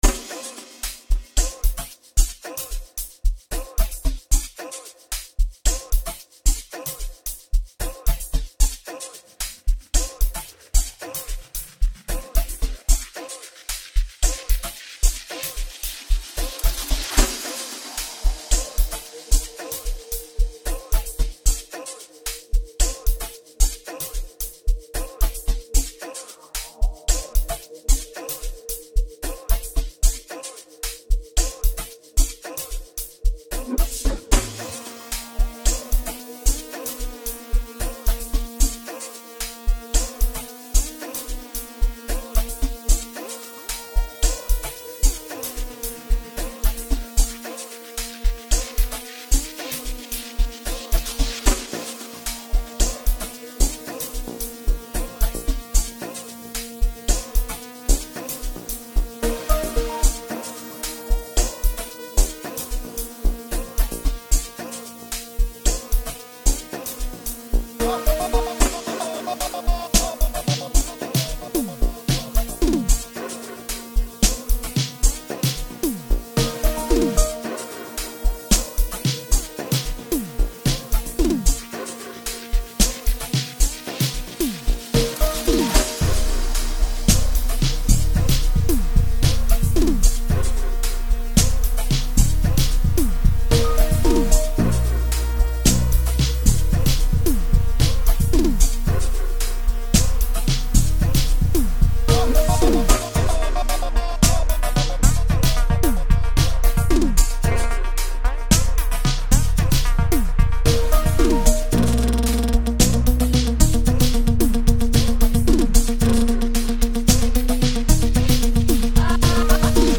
Multi Talented South African Amapiano hitmaker